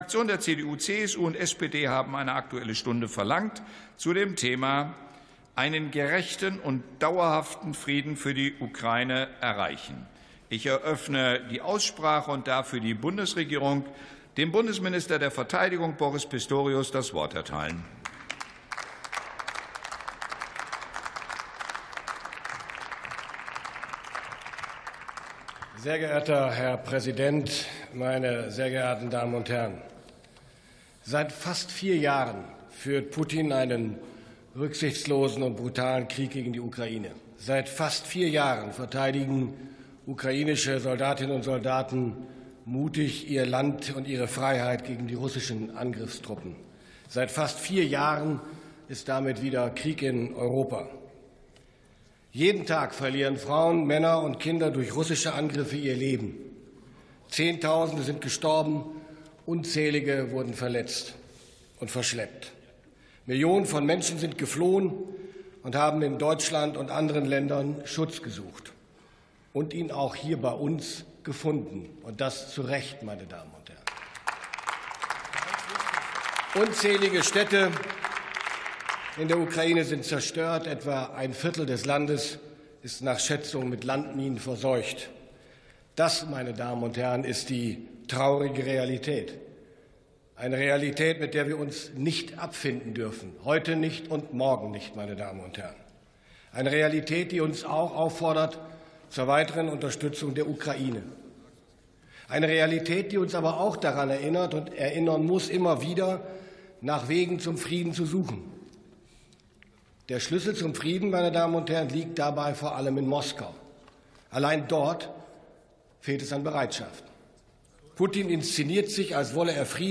47. Sitzung vom 04.12.2025. TOP ZP 3: Aktuelle Stunde: Frieden für die Ukraine ~ Plenarsitzungen - Audio Podcasts Podcast